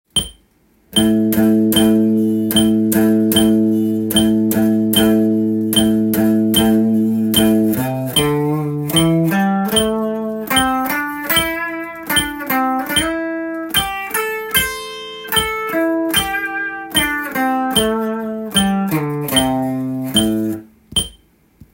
スケールでリズム練習tab
②のリズムは①の逆になります。